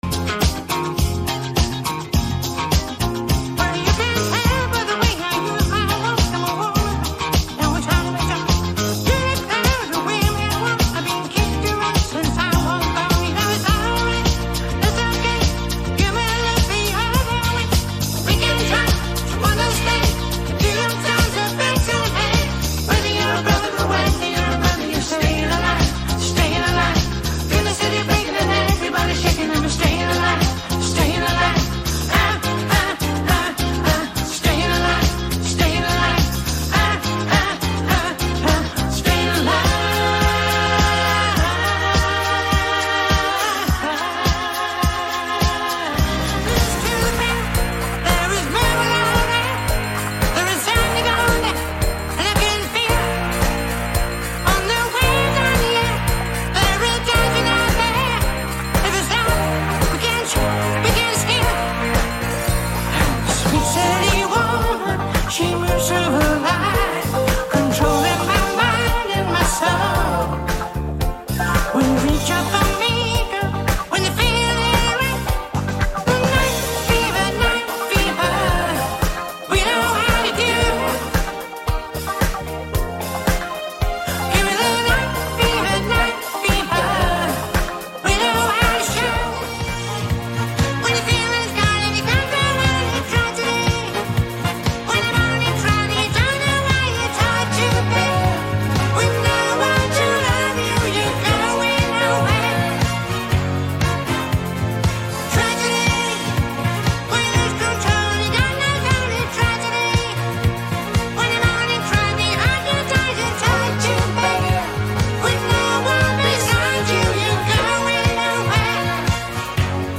Professional, fully self-contained show, quality sound
From soaring ballads to disco anthems